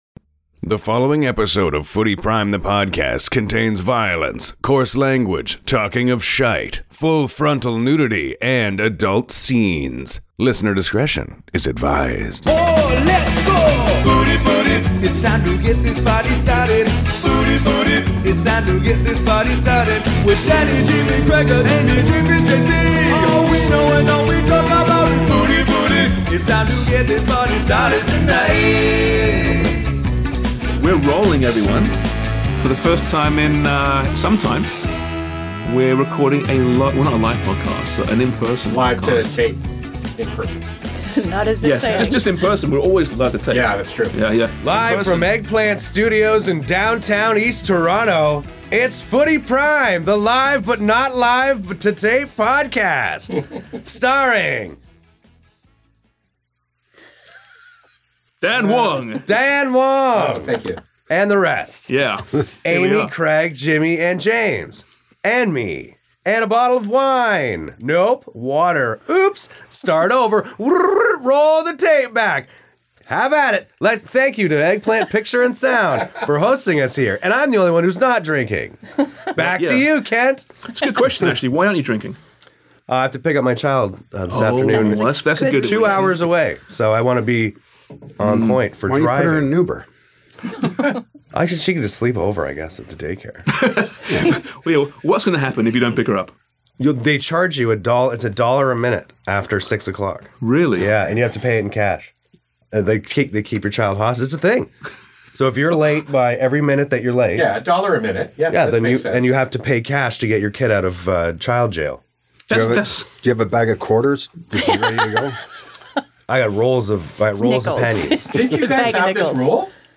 The FP are finally together in person!